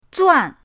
zuan4.mp3